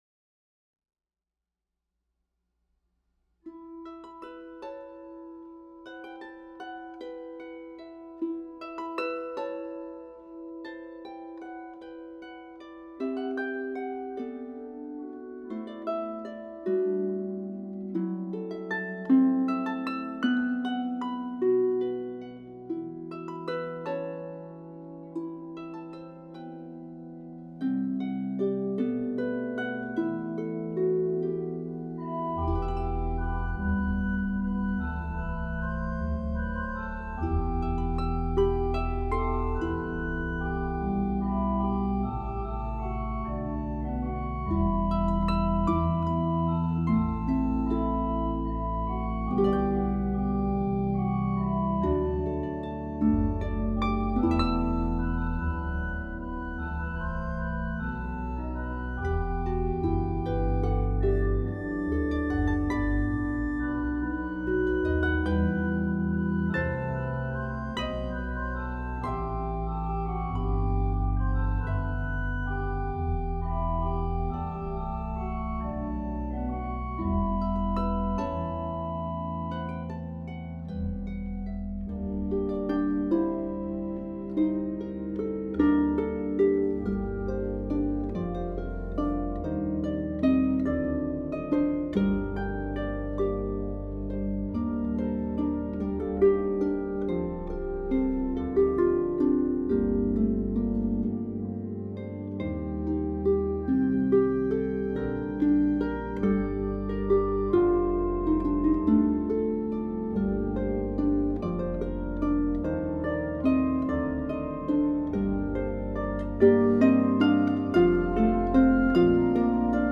for organ and pedal harp